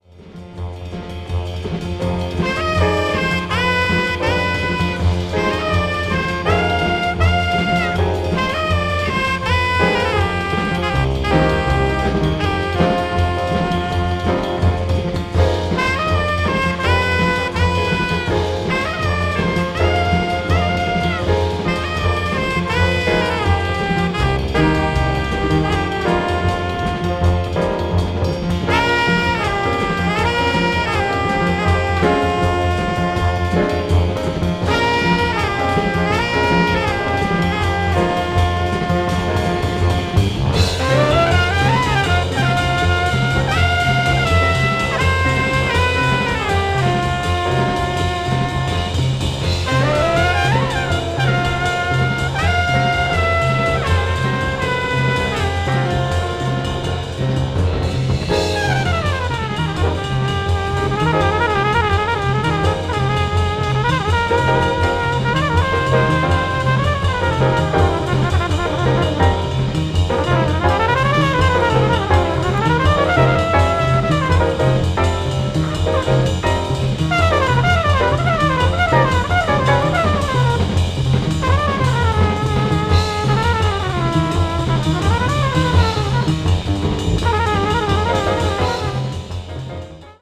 media : EX/EX(わずかにチリノイズが入る箇所あり)
avant-jazz   contemporary jazz   post bop   spiritual jazz